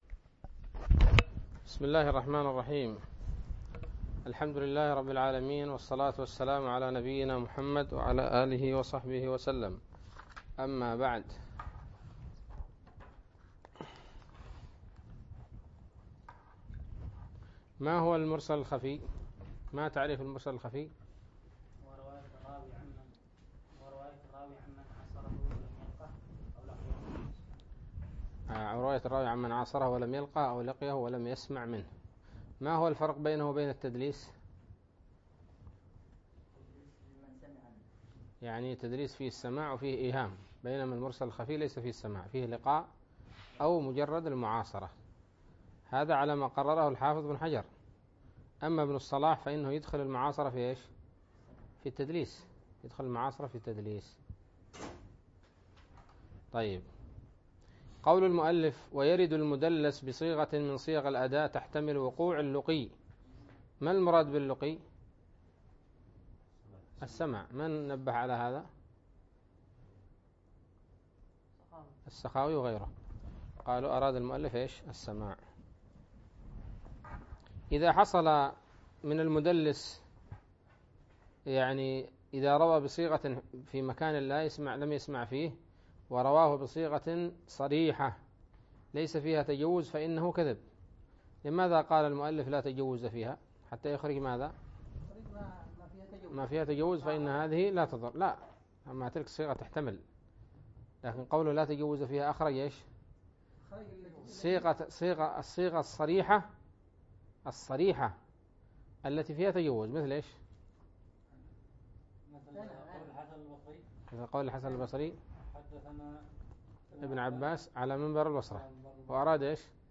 الدرس الواحد والعشرون من شرح نزهة النظر